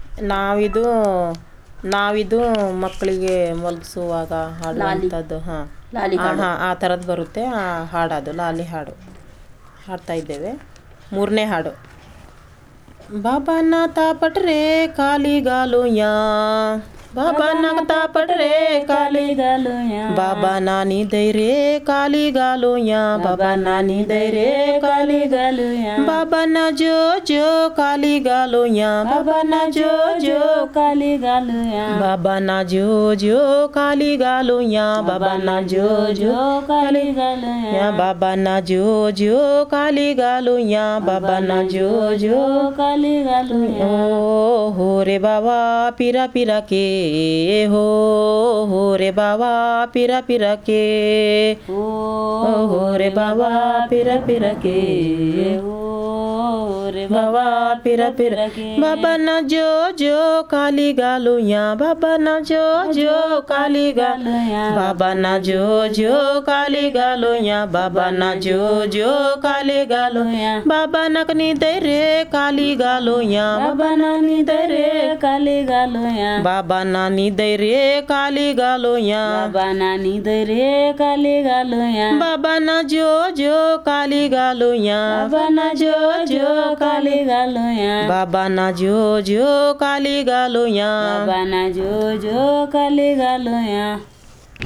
Performance of traditional folk song 'Babanna ta patre' (a lullaby